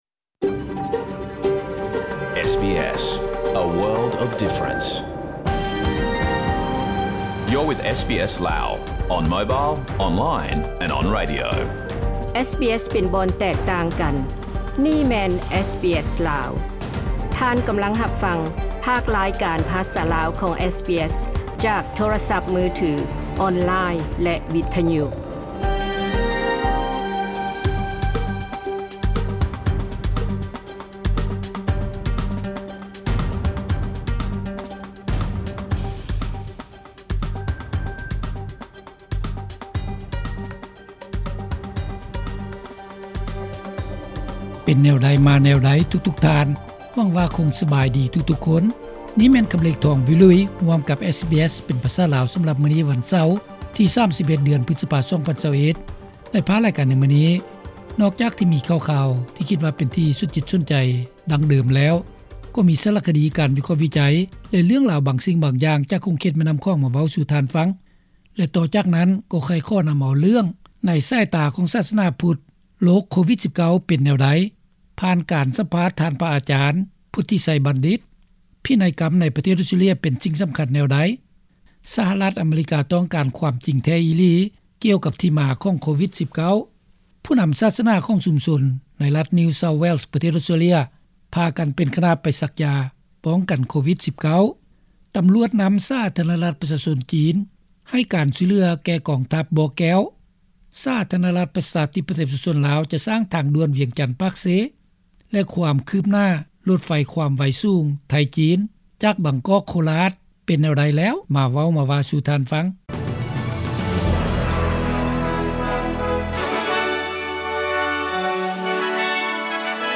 ຂ່າວສຳຮັບຣະຍະ 31-5-21